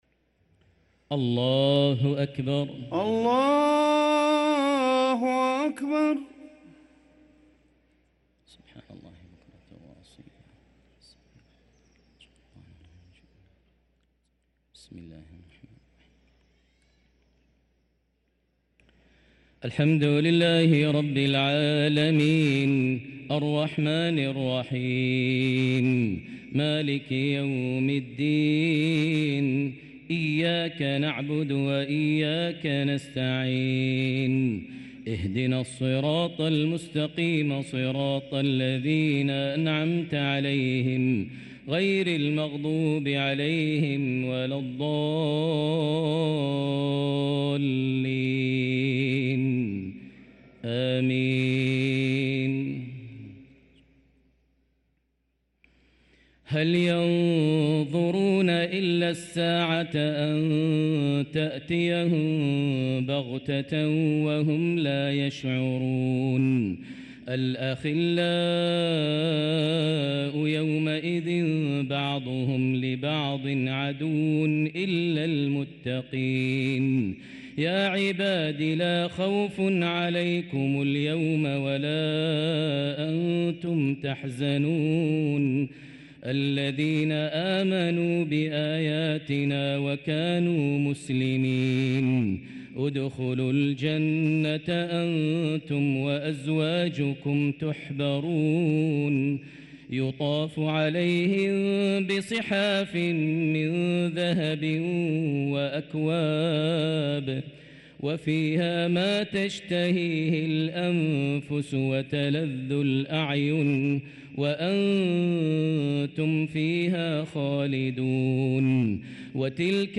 صلاة العشاء للقارئ ماهر المعيقلي 8 رجب 1445 هـ